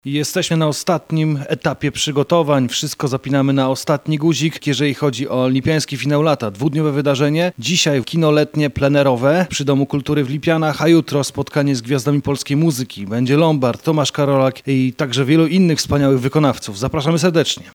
Burmistrz Bartłomiej Królikowski w rozmowie z Twoim Radiem „Ogłosił Gotowość” do prawdziwej muzycznej uczty i zabawy: